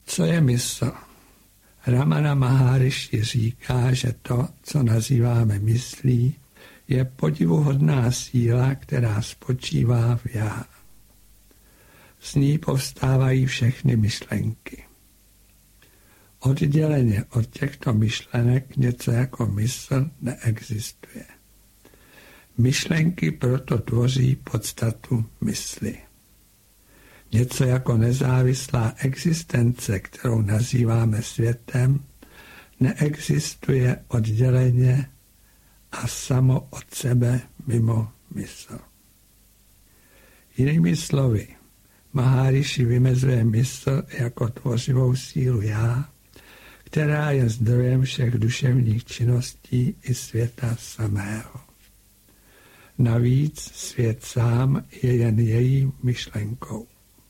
Audiokniha
Vysvobození mysli: Praxe vedoucí k vysvobození mysli Vysvobození mysli Ovládnutí mysli Zákonitosti, které mysl řídí Ovládnutá mysl jako základní předpoklad vysvobození Nejedná se o studiovou nahrávku.